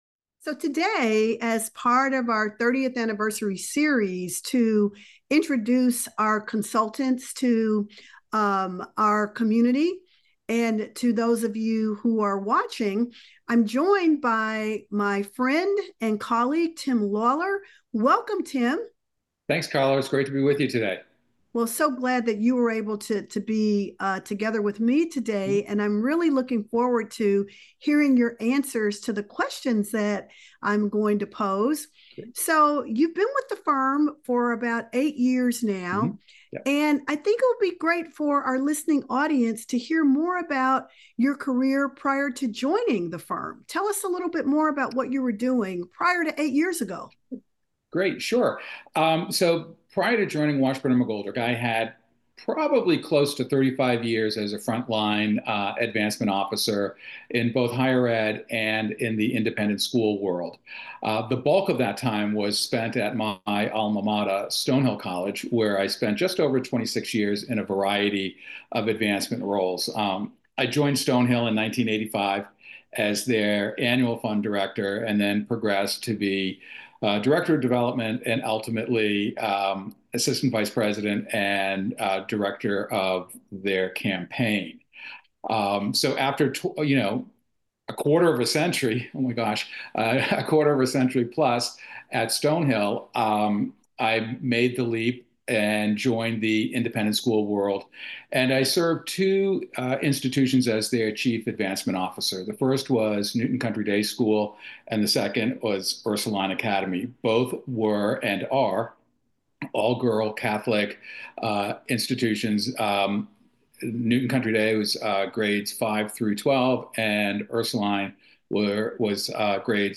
As part of Washburn McGoldrick’s 30th Anniversary year, each month we are featuring a brief conversation with one of our consultants.